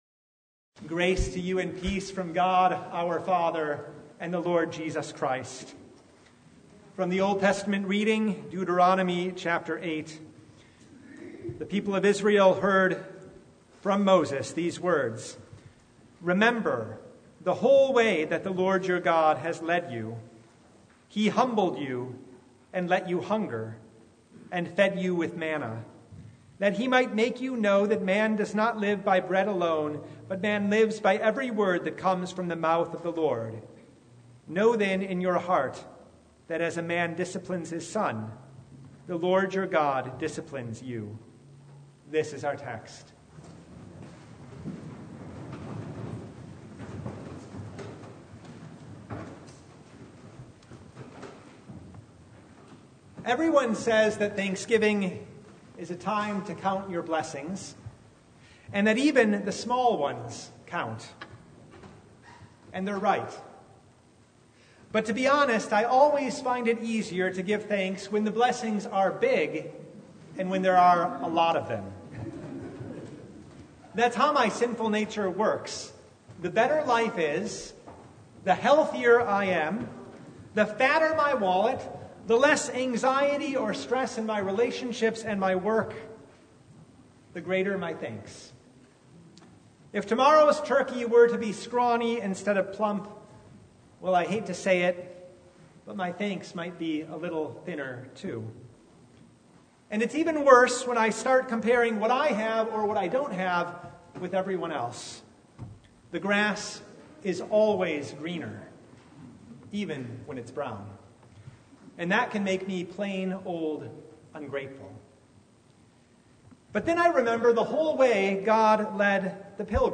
Service Type: Thanksgiving Eve